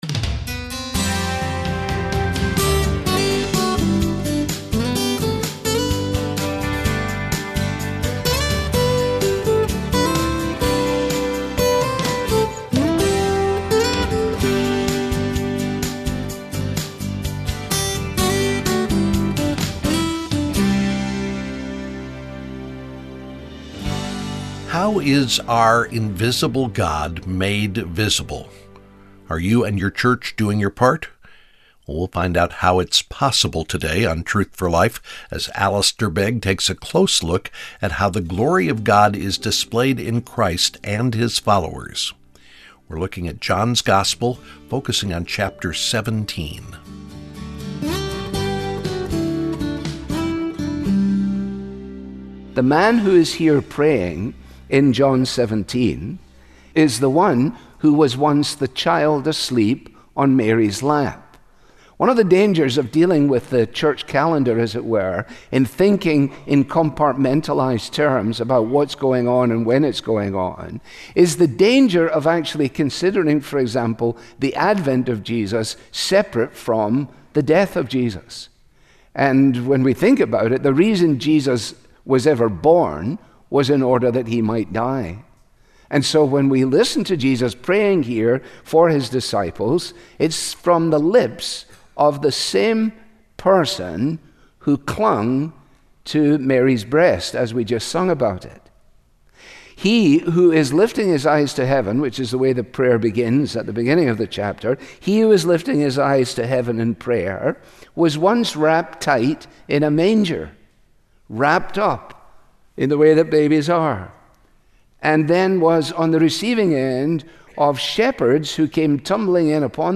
Truth For Life Daily Program Wonders of His Love Play episode April 2 Bookmarks View Transcript Episode Description How is our invisible God made visible?